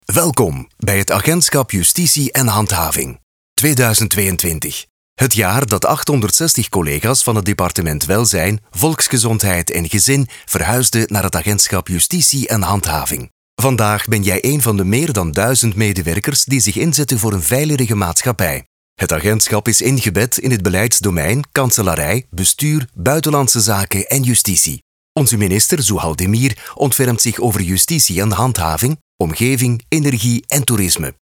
Cálida, Profundo, Seguro, Maduro, Accesible
Corporativo